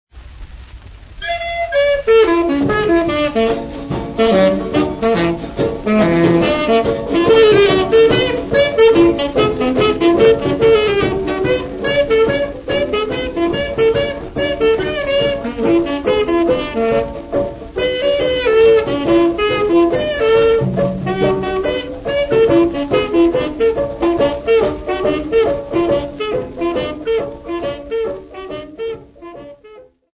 Umtali Chipisa Band
Popular music--Africa
Dance music
Dance music--Caribbean Area
Field recordings
Africa Zimbabwe Mutare f-sa
sound recording-musical
A small dance band